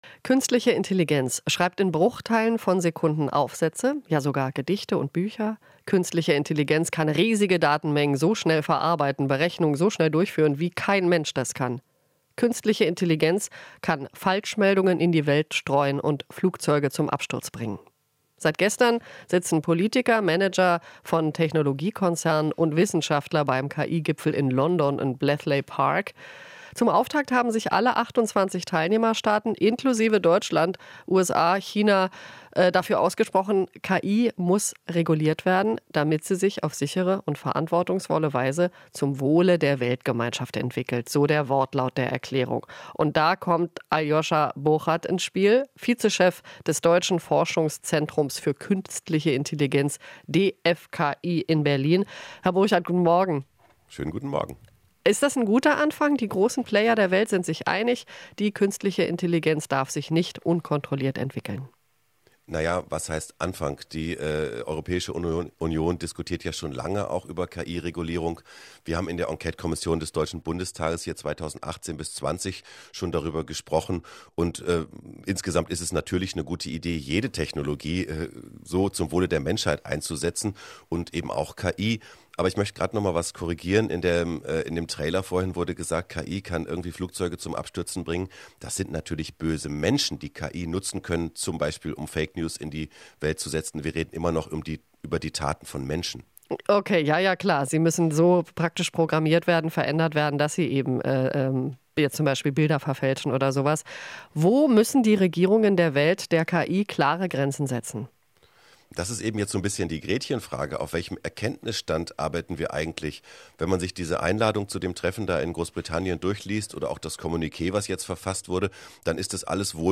Interview - KI-Experte: Lieber in Forschung investieren als alles zu regulieren